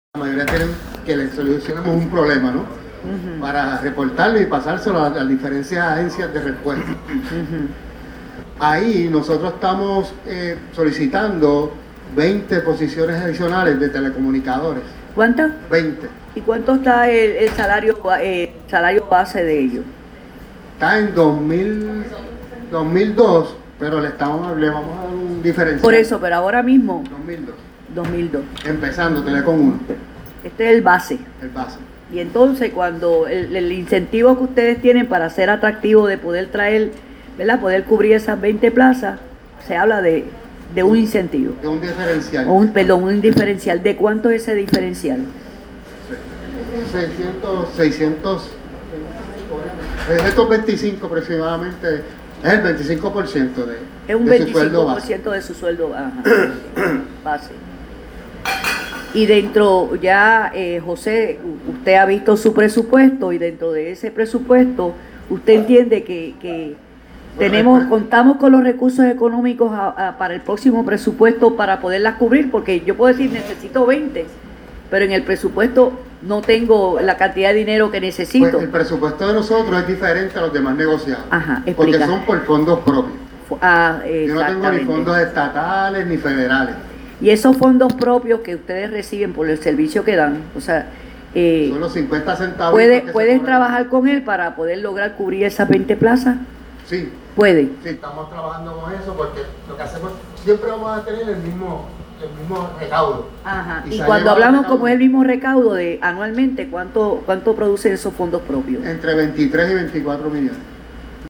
“Estamos convencidos de que vamos a poder tener esos recursos porque son necesarios”, dijo el titular NMEAD durante las vistas de presupuesto del senado (sonido)
Ángel Jiménez Colón, comisionado del NMEAD, indicó: